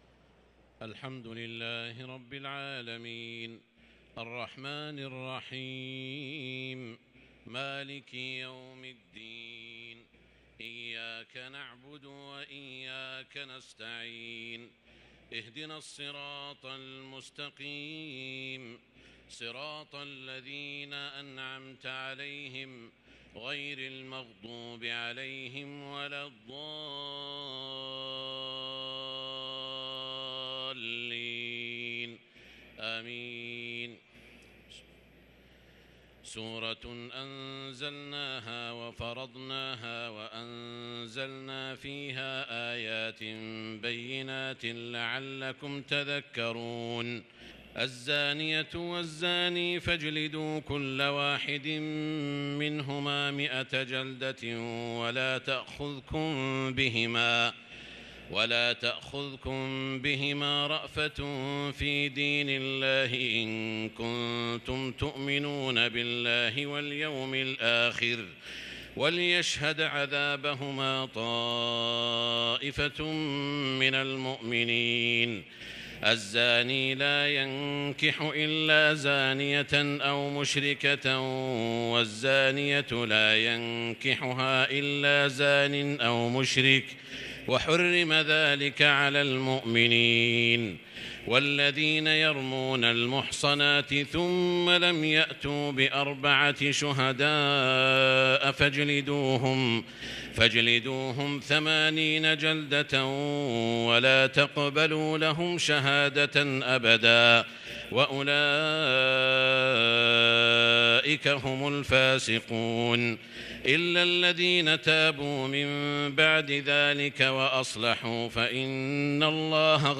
صلاة التراويح ليلة 22 رمضان 1443 للقارئ سعود الشريم - الثلاث التسليمات الاولى صلاة التهجد
تِلَاوَات الْحَرَمَيْن .